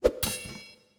TD_ButtonSound.wav